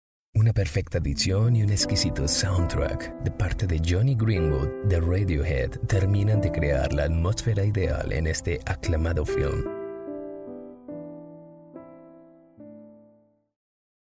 I'll gladly take care of your voice-over requirements (Neutral Latin American Spanish/Neutral American English), be it for your personal or commercial use.
Sprechprobe: Sonstiges (Muttersprache):